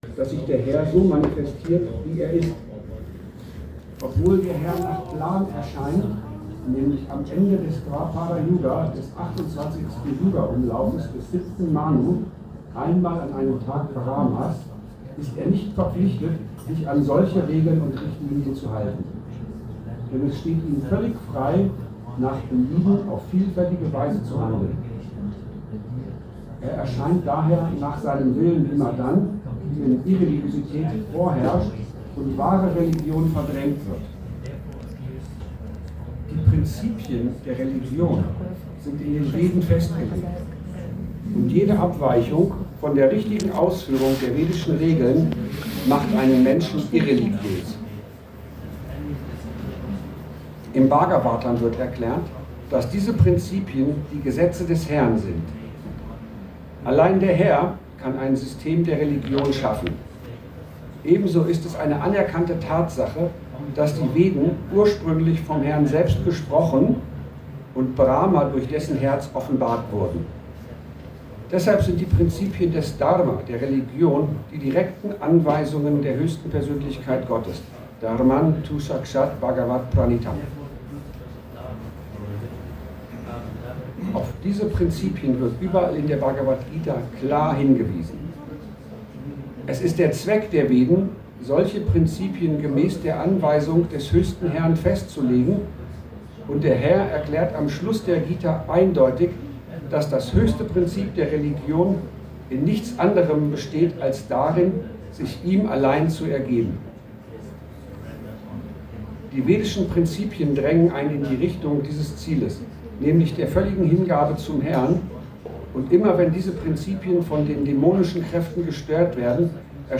Schönheit und Weisheit des Ramayana – Vortrag zum Ramayana